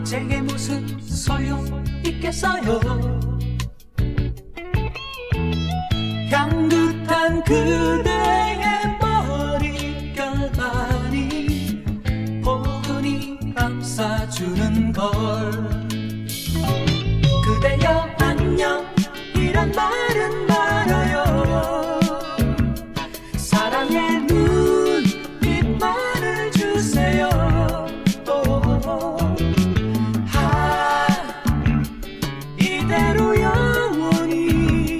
# Trot